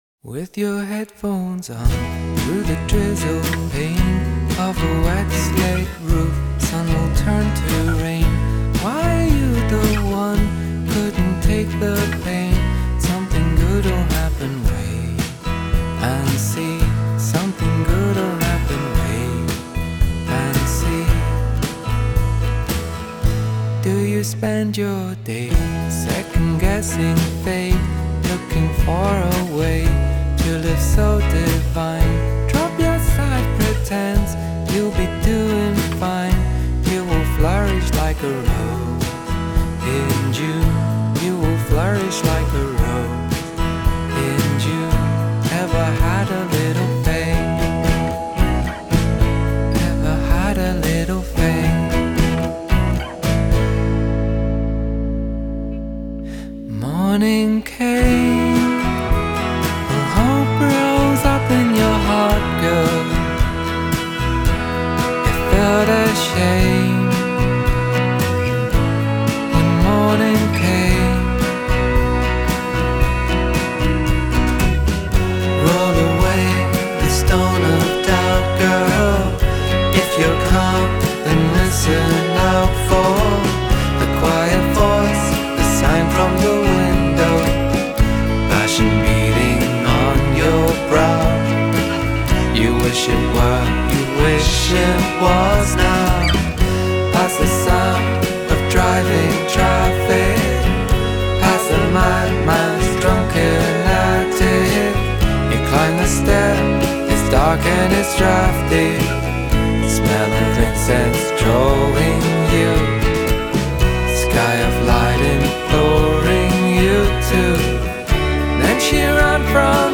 Scottish indie stalwarts